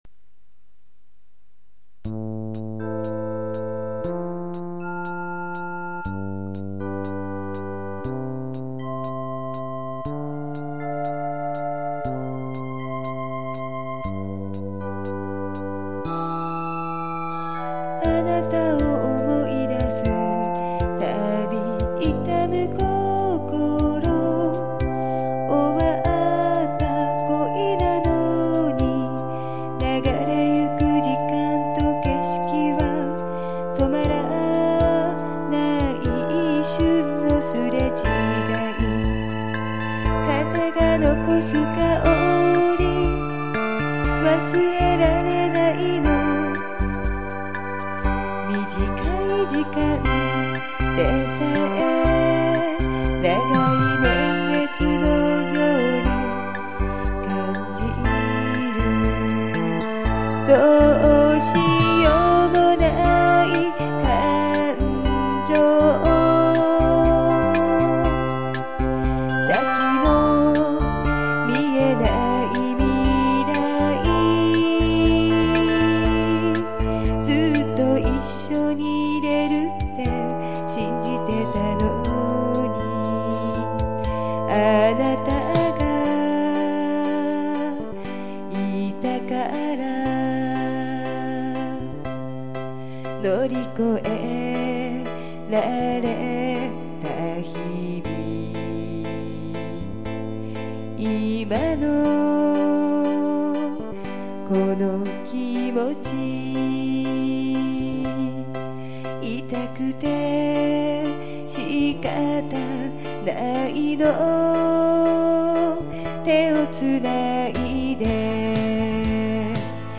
女性らしく歌ったつもりなんですが…どうでしょう？